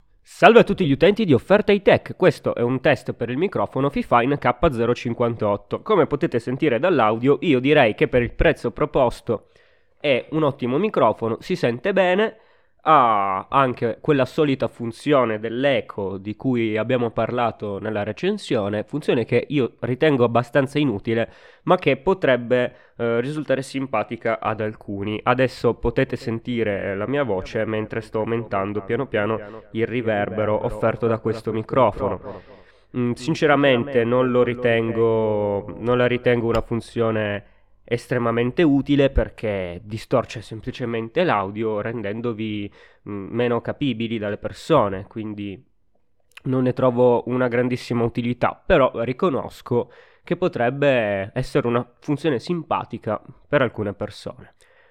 • Polar Pattern: Cardioid
Beh, posso garantirvi che per il costo offerto la qualità audio è una delle migliori che si possano trovare.
fifine_k058_test_audio.ogg